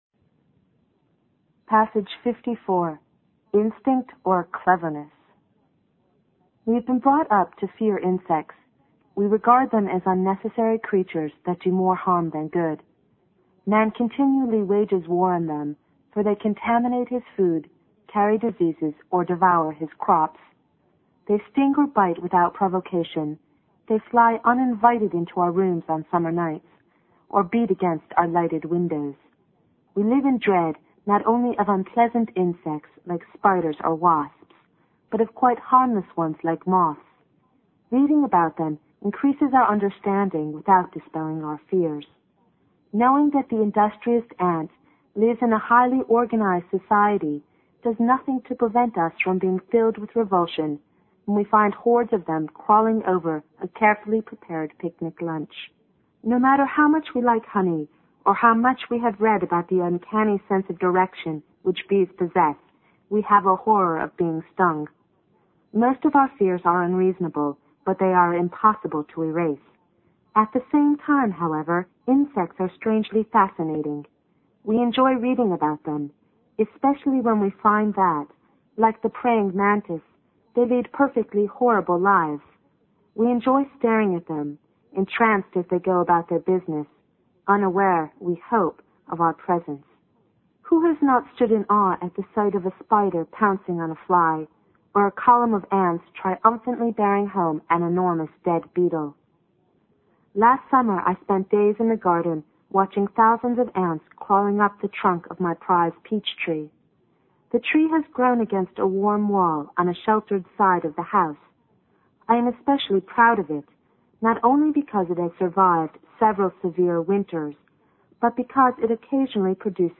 新概念英语85年上外美音版第三册 第54课 听力文件下载—在线英语听力室